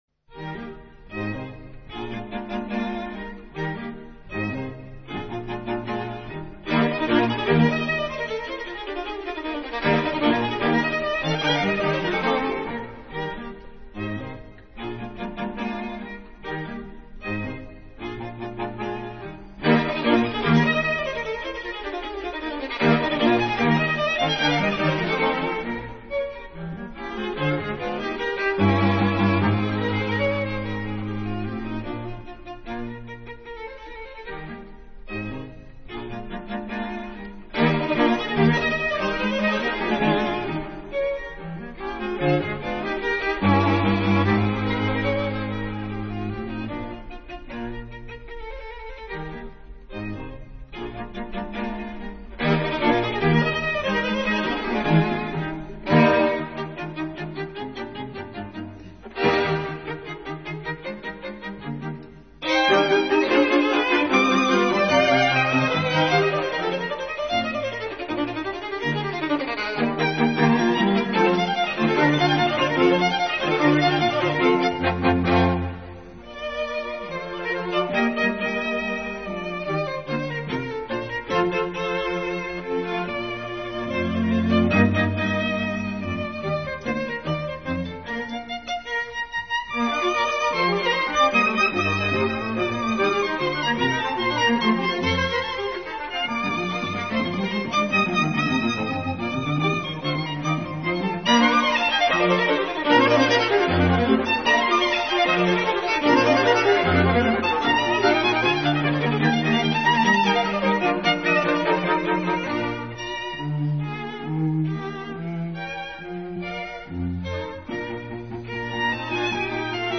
String Quartet in E flat major
Allegro vivace